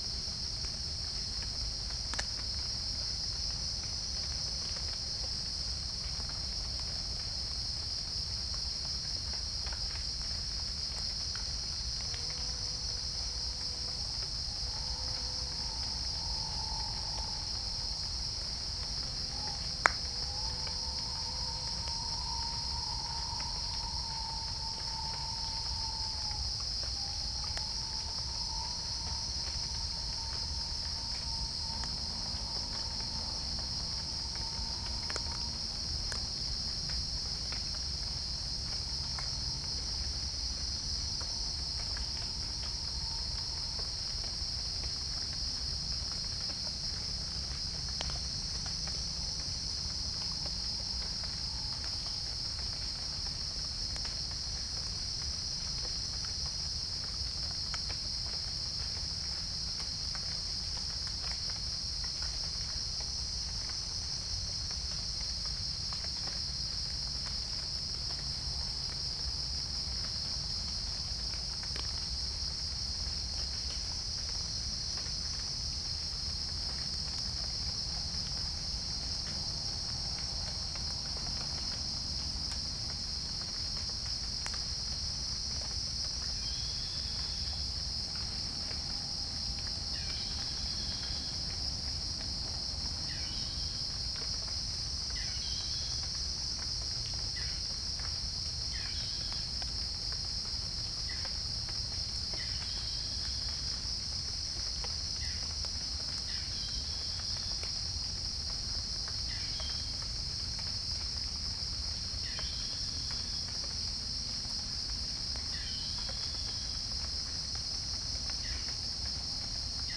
Geopelia striata
Pycnonotus goiavier
Orthotomus ruficeps
Halcyon smyrnensis
Dicaeum trigonostigma